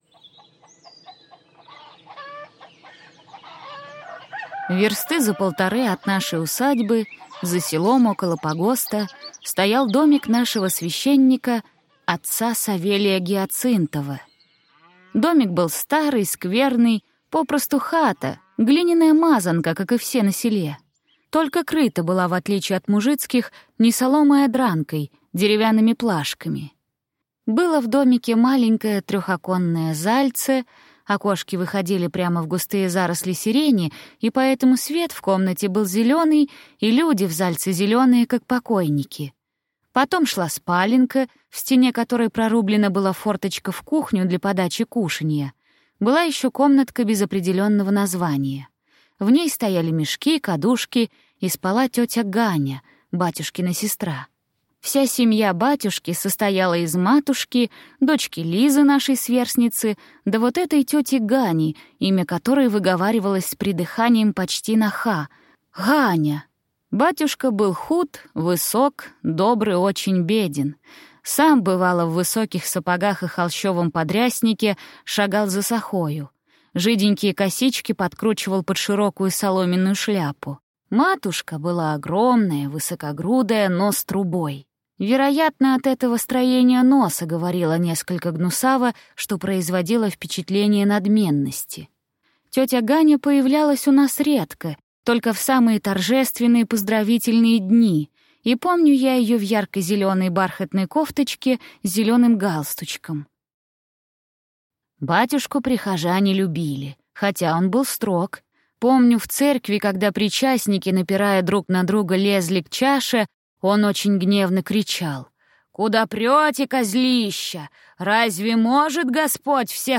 Аудиокнига Вурдалак | Библиотека аудиокниг